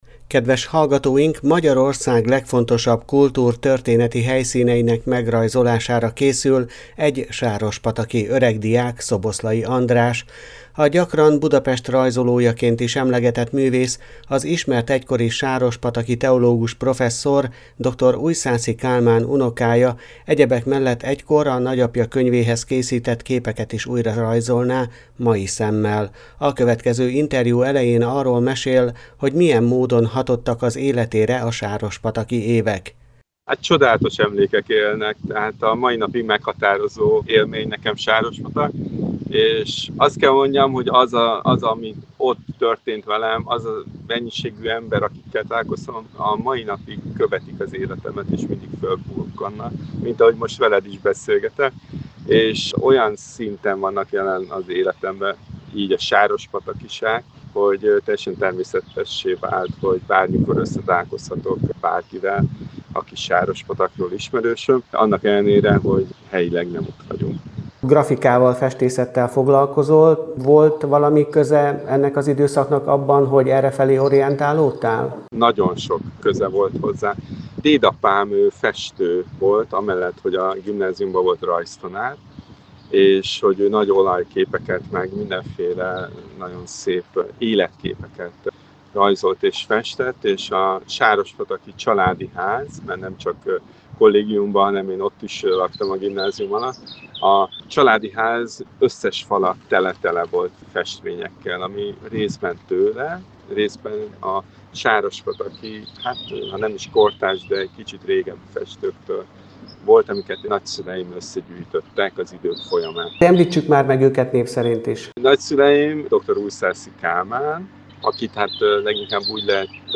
A következő interjú elején arról mesél, hogy milyen módon hatottak az életére a sárospataki évek.